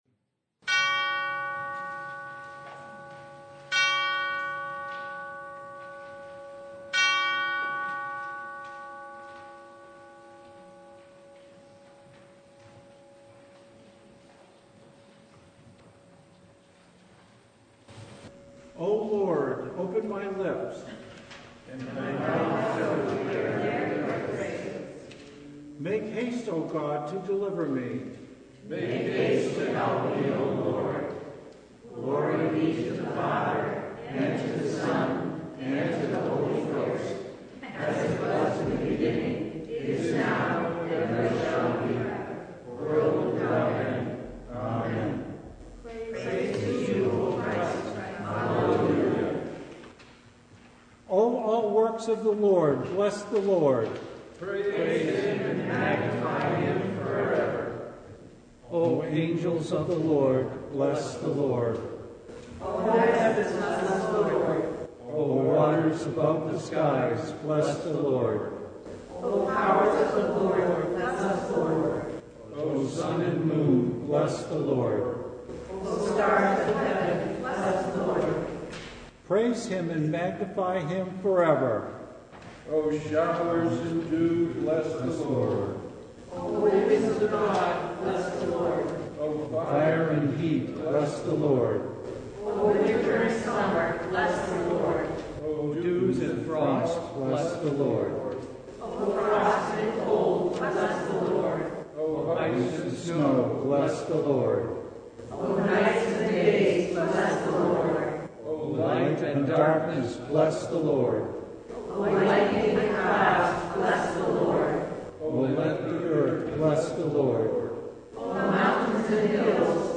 Preacher: Visiting Pastor Passage: Luke 17:11-19 Service Type